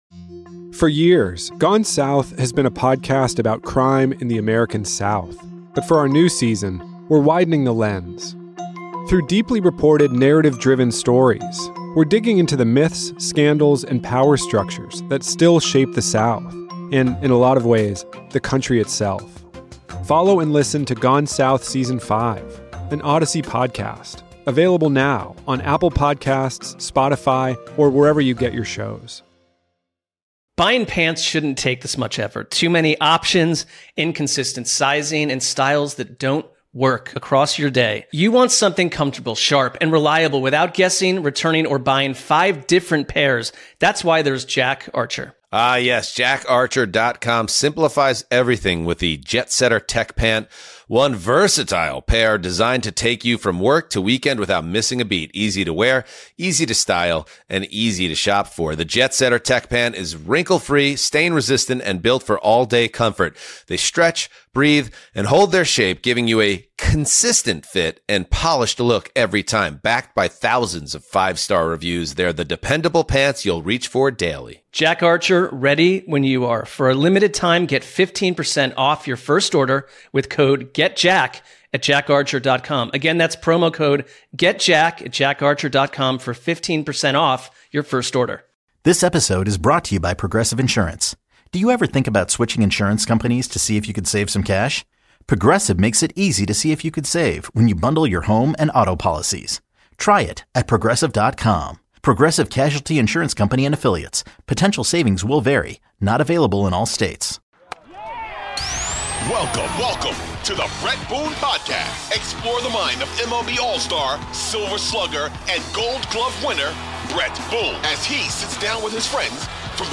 Bret Boone discusses with Yankees Manager, and little brother, Aaron Boone on the affect that the ABS system to having on his team, and the MLB as a whole.